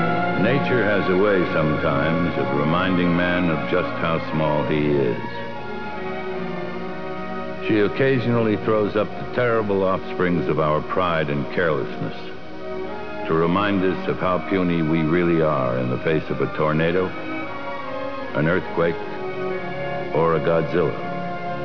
Godzilla movies came from Raymond Burr at the end of the US’s Godzilla 1985.
when discussing Godzilla’s apparent demise, Burr says: